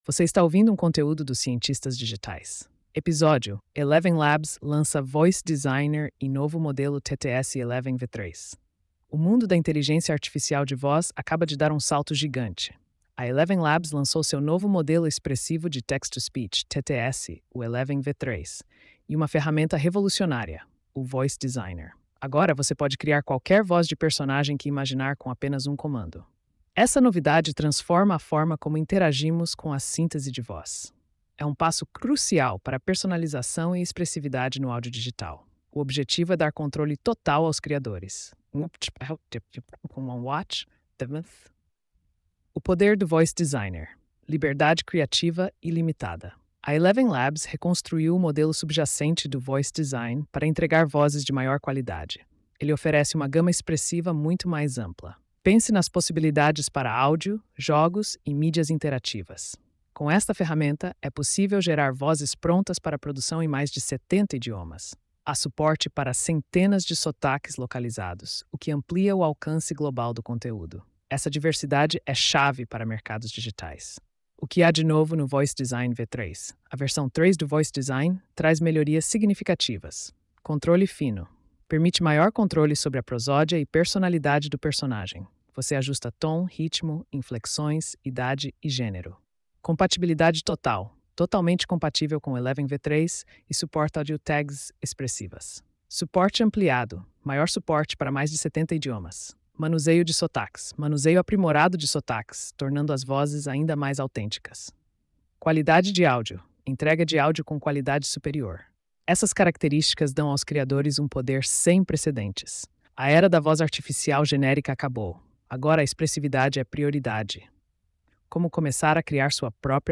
post-3260-tts.mp3